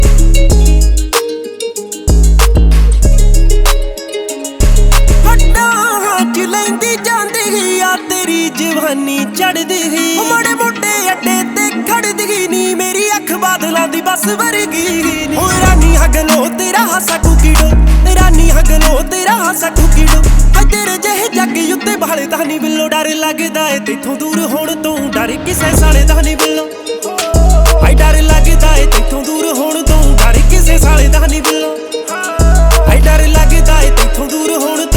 Жанр: Поп / Инди / Местная инди-музыка
# Punjabi Pop